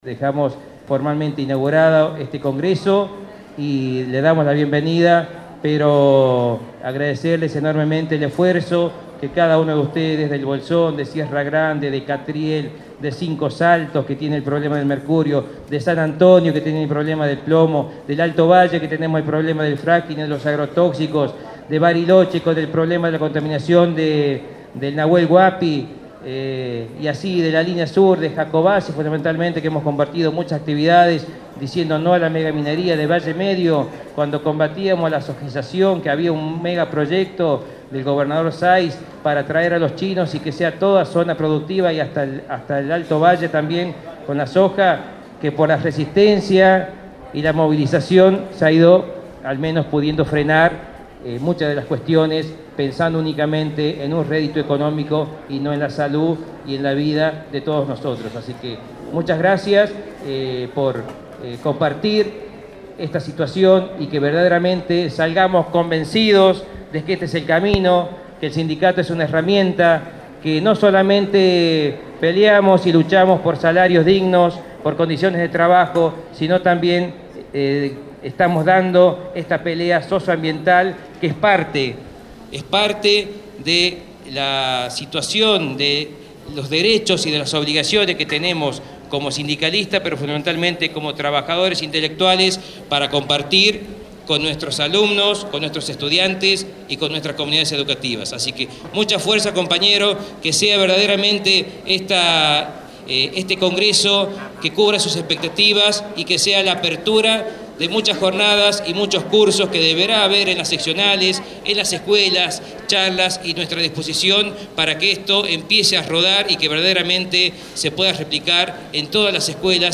UnTER) - Apertura Congreso Socioambiental cuerpo: Primer Congreso Socioambiental // Fiske Menuco // 5 y 6 de mayo de 2016